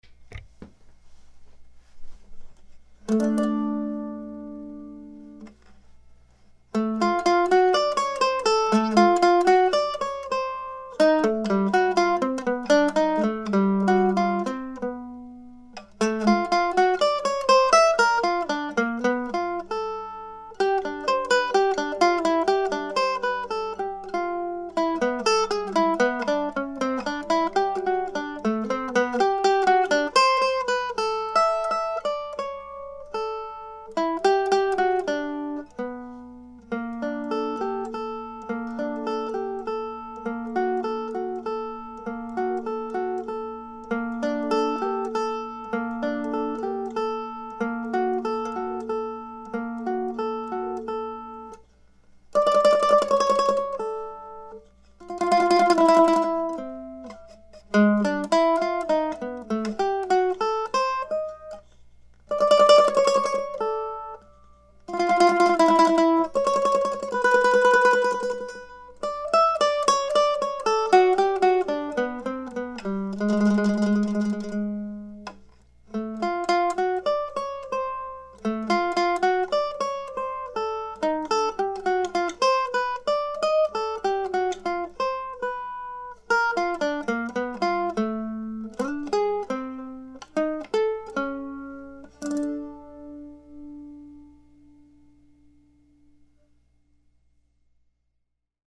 Another addition to my ongoing series of short Deer Tracks solo mandolin pieces, from a couple of weeks ago.